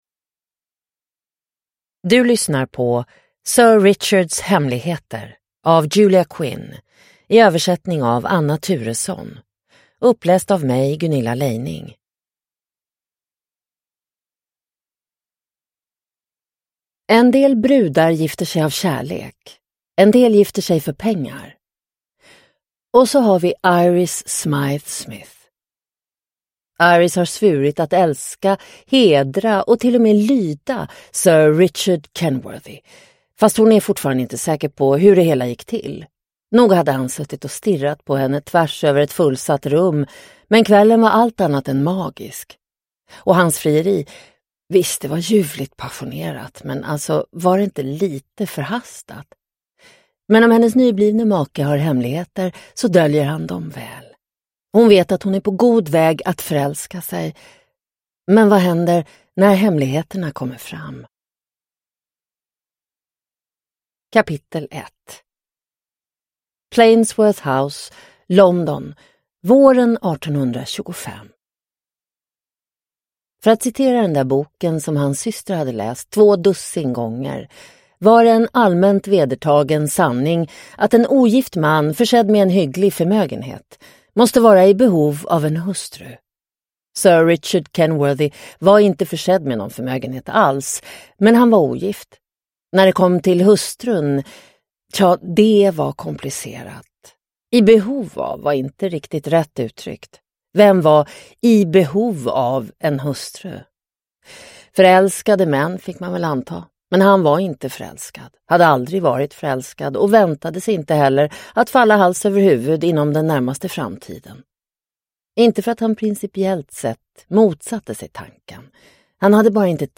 Sir Richards hemligheter – Ljudbok – Laddas ner